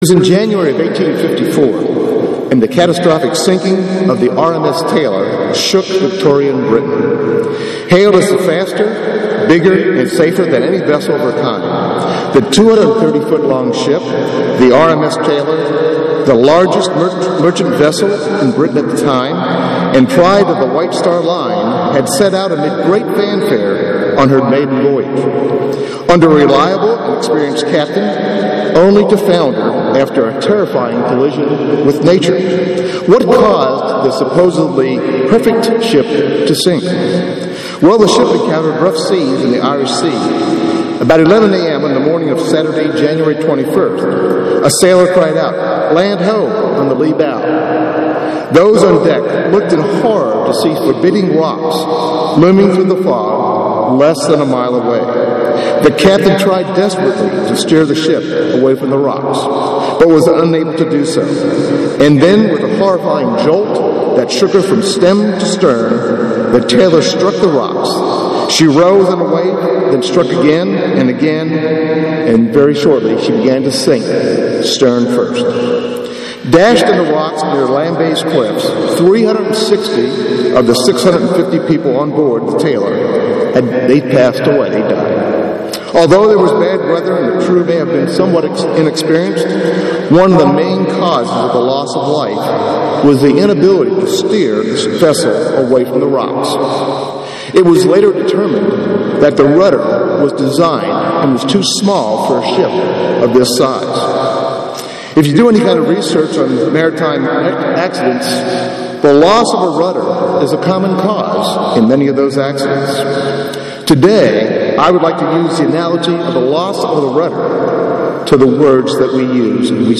Given in Atlanta, GA Buford, GA
UCG Sermon Studying the bible?